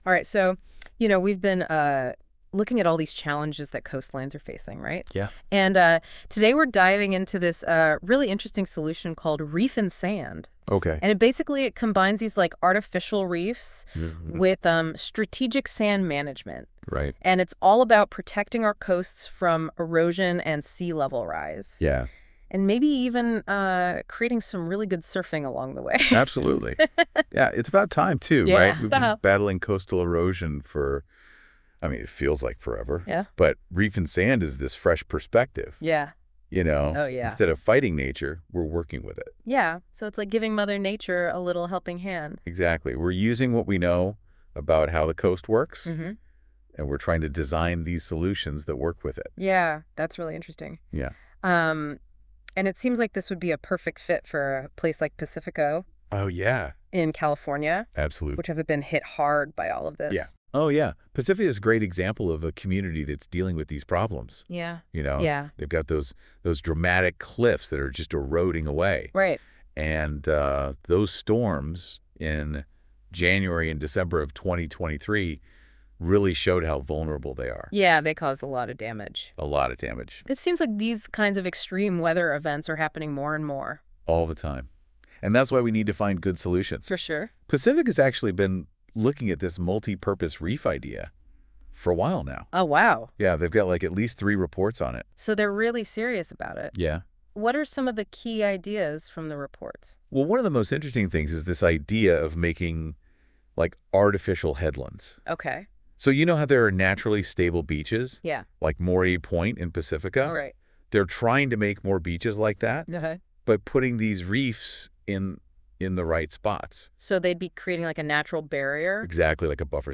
NEWS: 2024-11-20 Listen to a podcast discussion of New Waves for Pacifica and other Developed California Shores
CLICK HERE to hear the podcast (created using AI only)